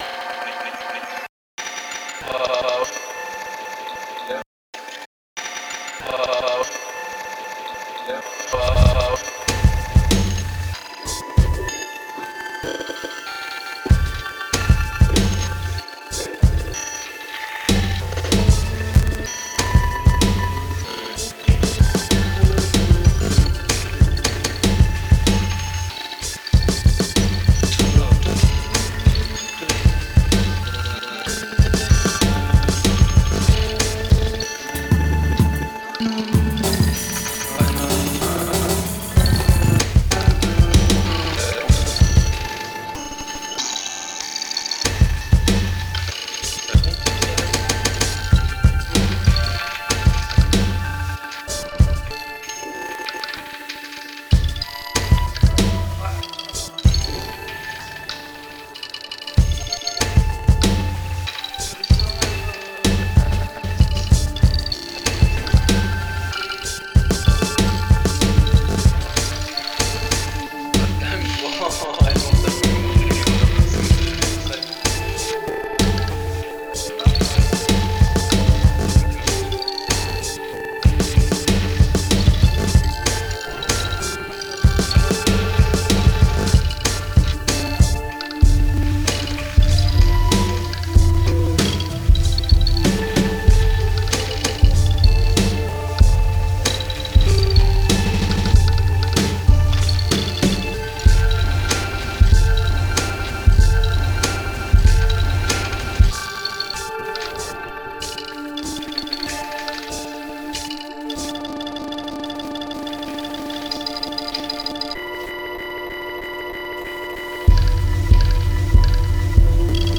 style: electronica, post-hiphop, downtempo